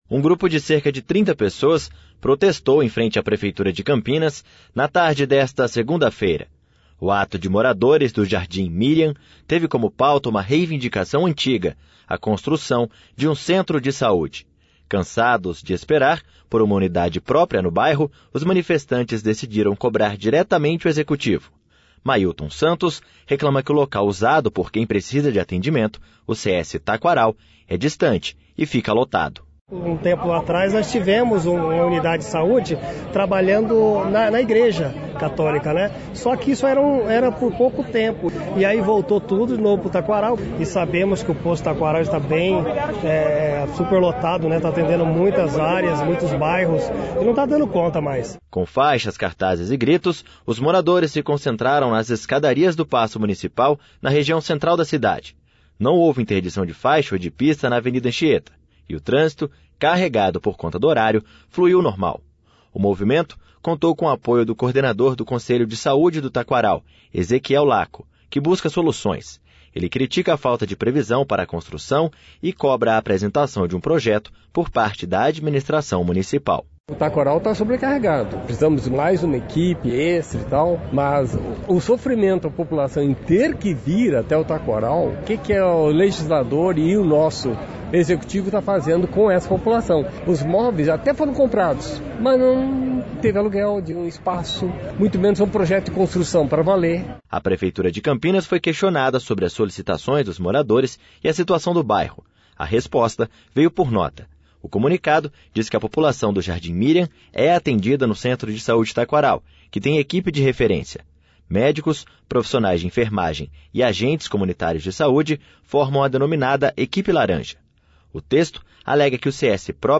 Com faixas, cartazes e gritos, os moradores se concentraram nas escadarias do Paço Municipal, na região central da cidade.